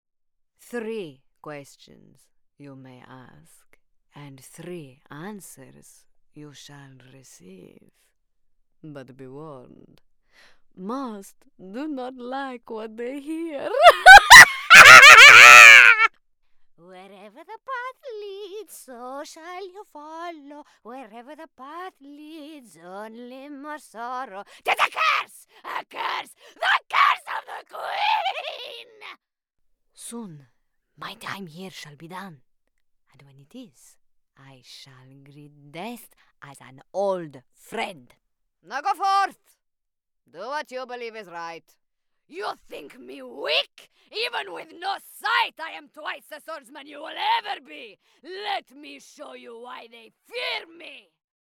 Voice Reel
Italian Elder - Video Game